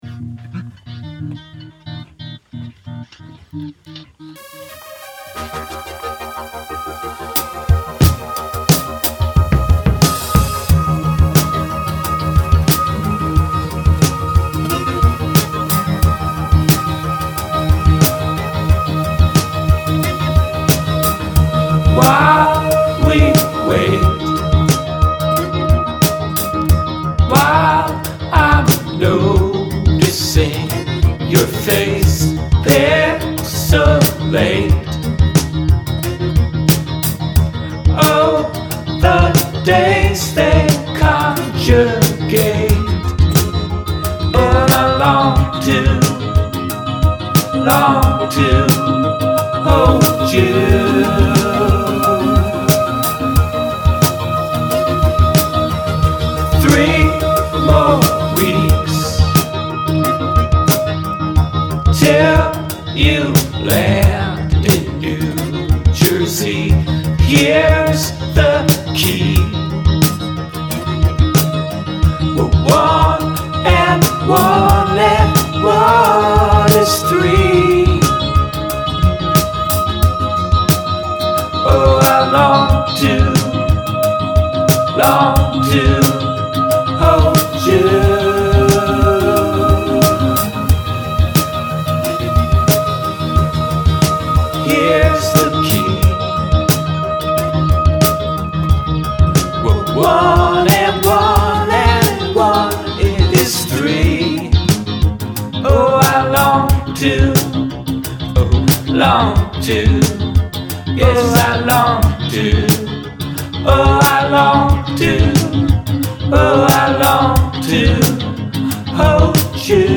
I tuned my guitar down, but it's something like this
chorus: F, Am, F, C, C, G
verse, chorus, verse, chorus, short verse, chorus
It's very 80s somehow. The beats are almost unsustainably funky! What a groove!